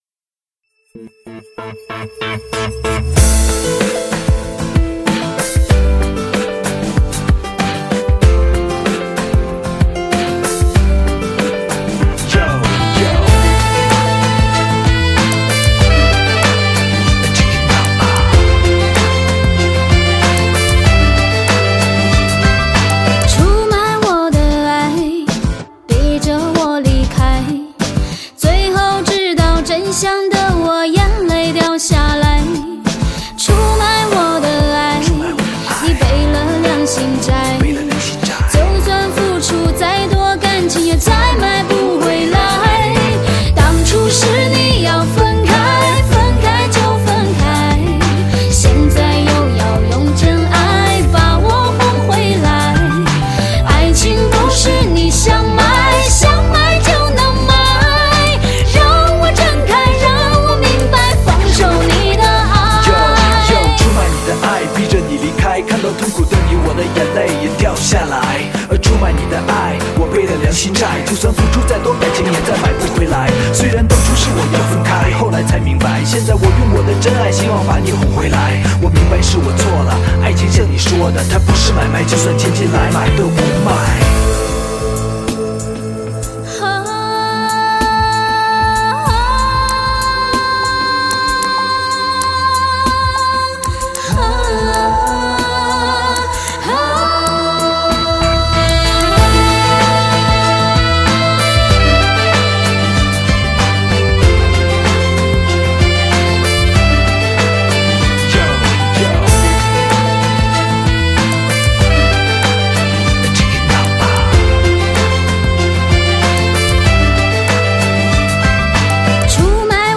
她那高亢豪迈的嗓音，诠译情歌有着穿透的力量
剔透声线与唯美旋律完美演绎最永恒无限的真情。